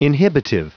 Prononciation du mot inhibitive en anglais (fichier audio)
Prononciation du mot : inhibitive